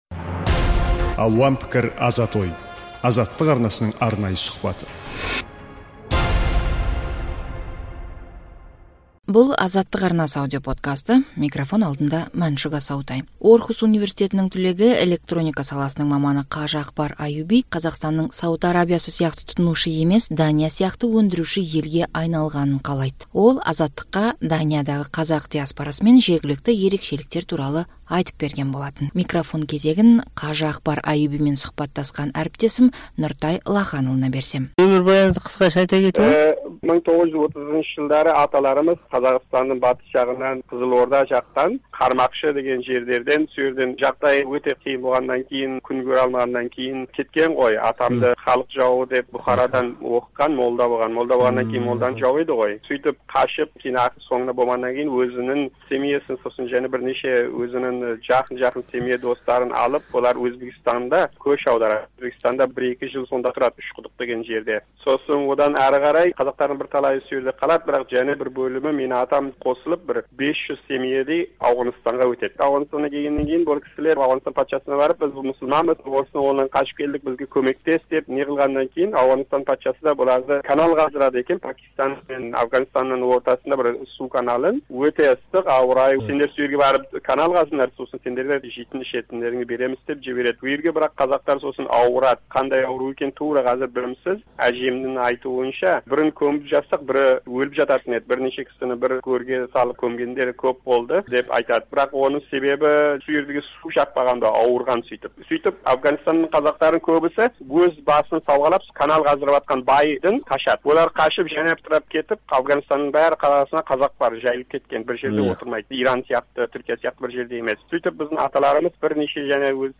Азаттыққа берген сұхбаты.